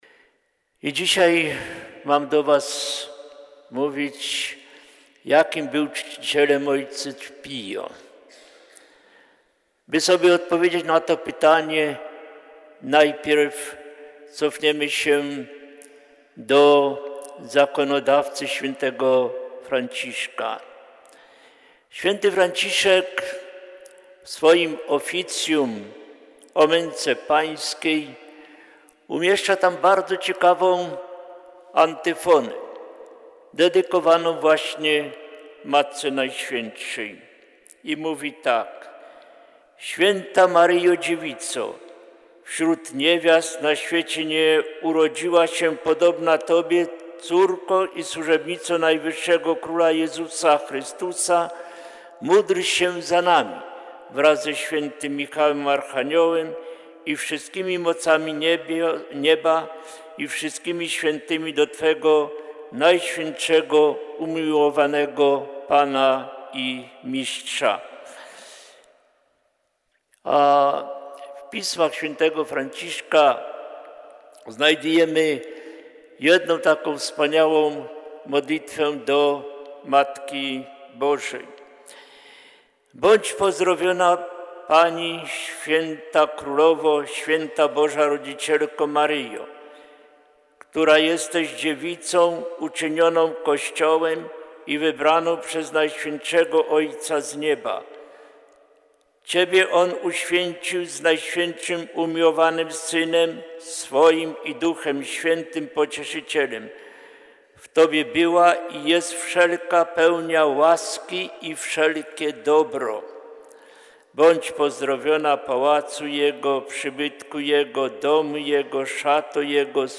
Rekolekcje Adwentowe 2016 – relacja audio